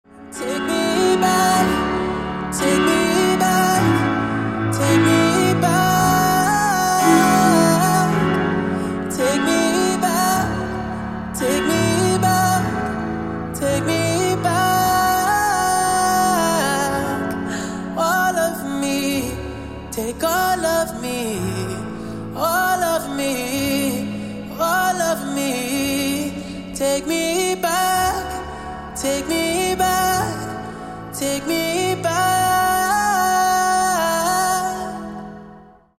RnB Женский голос